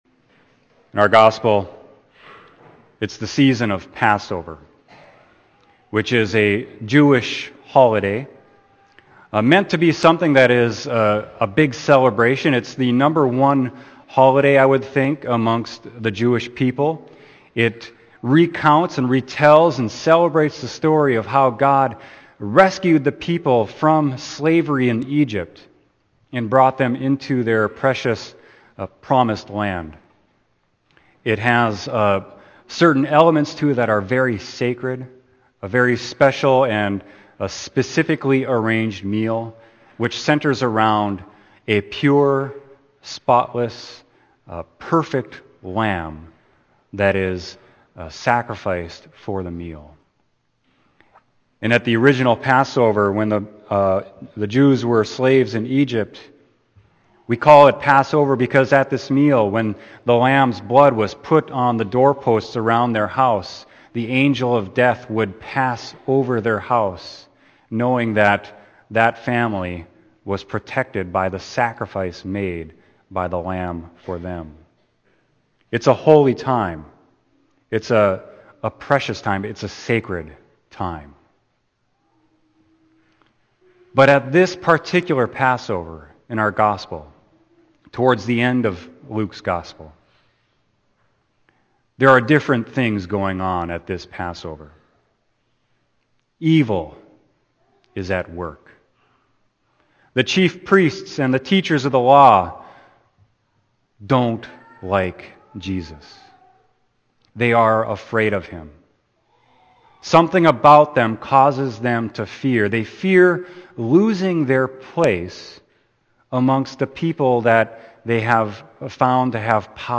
Sermon: Luke 22.1-13